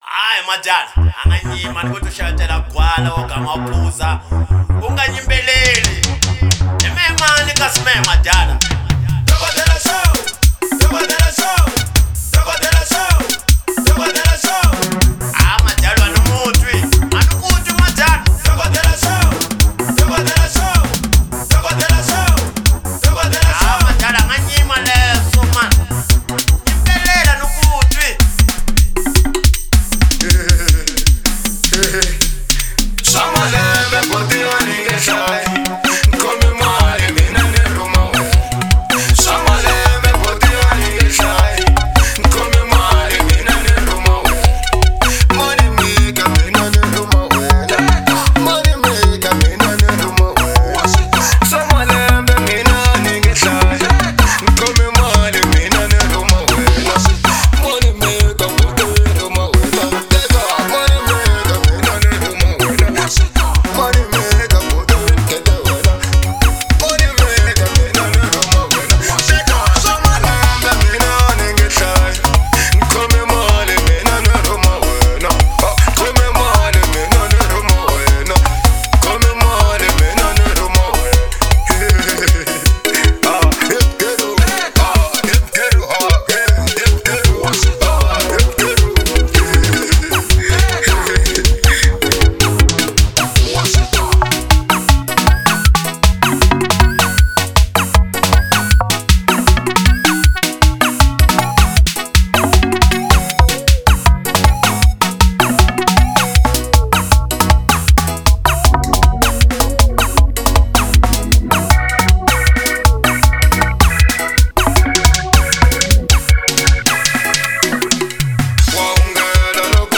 04:46 Genre : Xitsonga Size